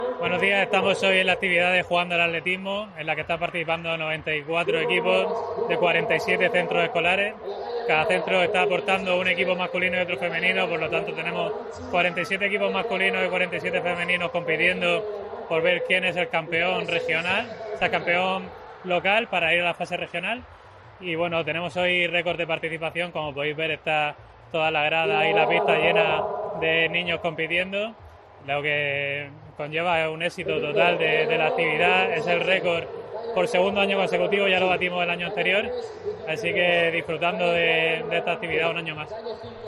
Enlace a Declaraciones del concejal de Deportes, José Martínez, sobre Jugando al Atletismo